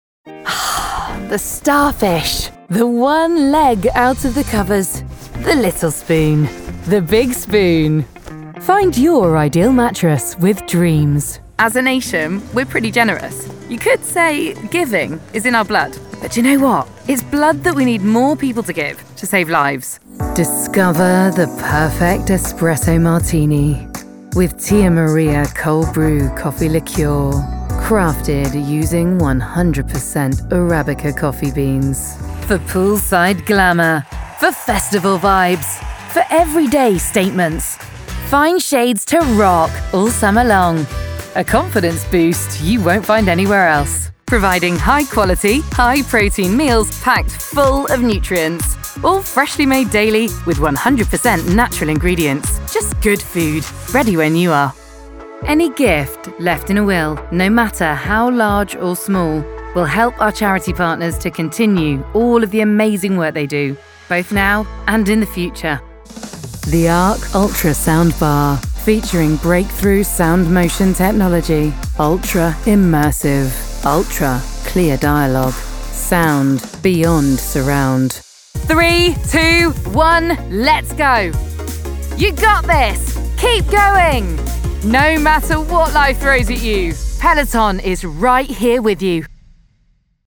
Commercial Showreel
Female
Neutral British
Husky (light)
Friendly